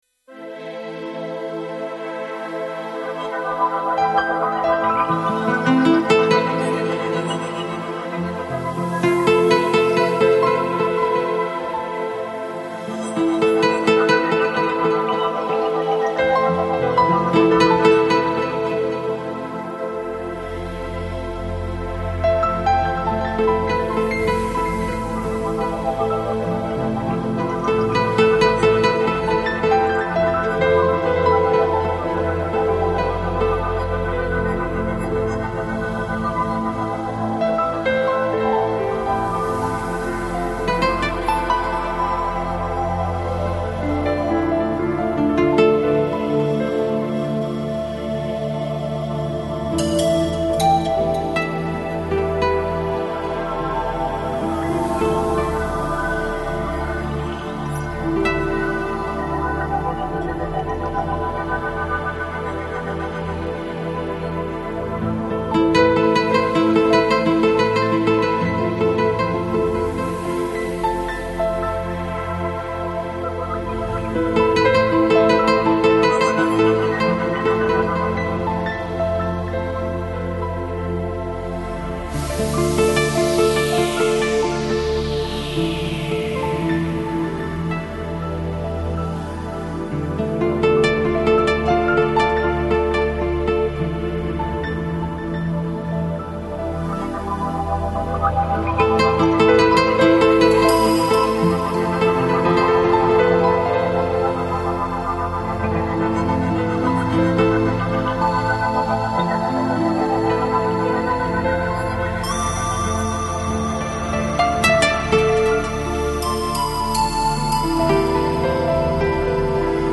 Жанр: Lounge, Chill Out, Downtempo, Balearic